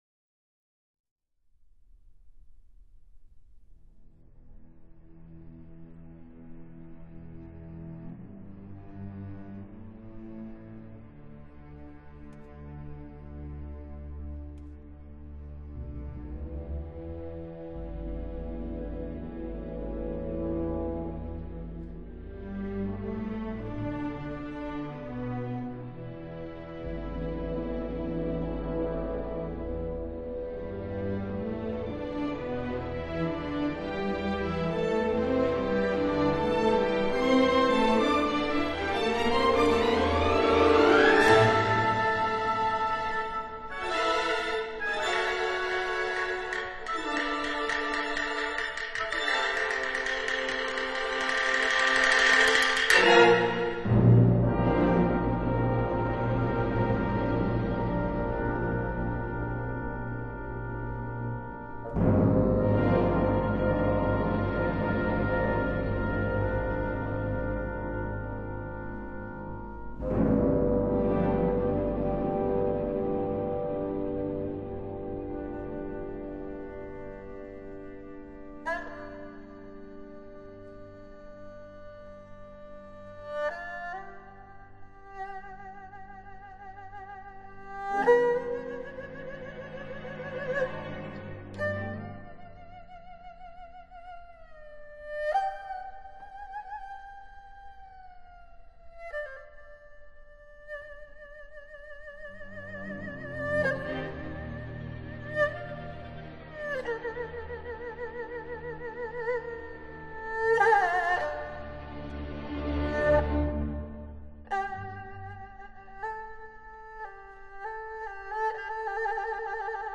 二胡与交响乐队